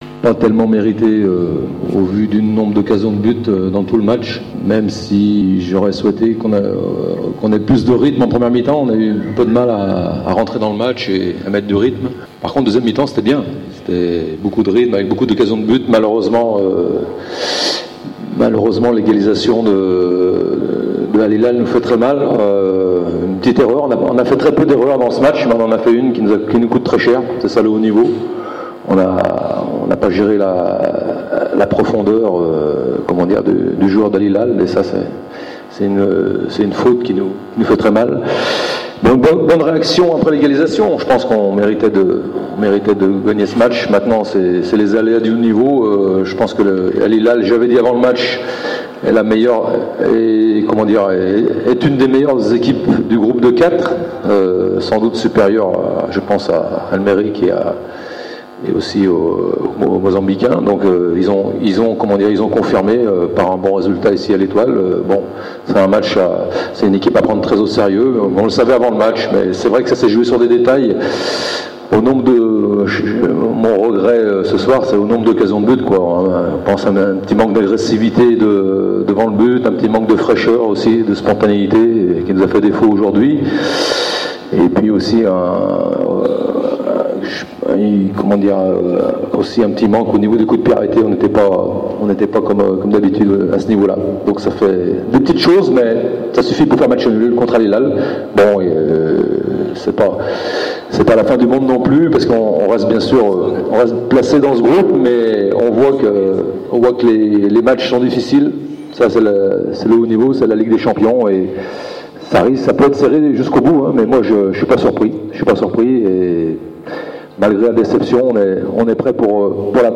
أكد مدرب النجم الساحلي هيبار فيلود خلال الندوة الصحفية إثر مواجهة الهلال السوداني أن جزئيات صغيرة قد تحكمت في نتيجة اللقاء و جعلت المنافس يقتلع التعادل في توقيت صعب .